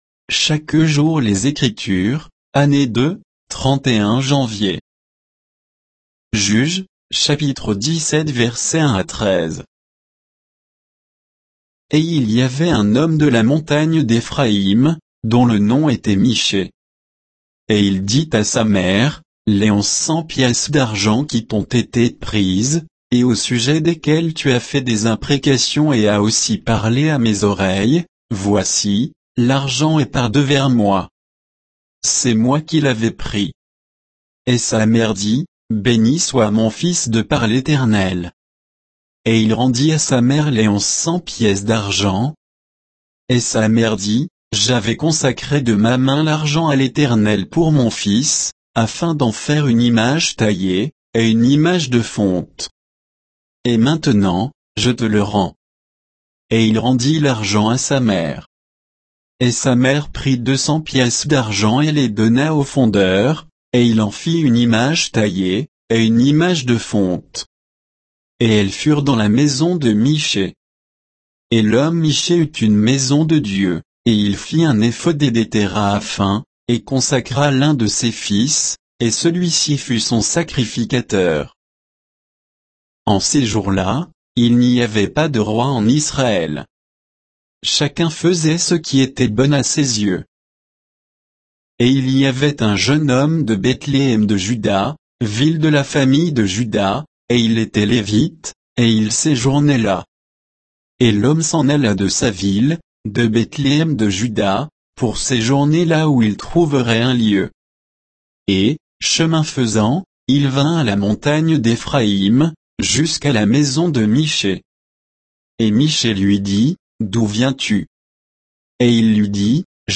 Méditation quoditienne de Chaque jour les Écritures sur Juges 17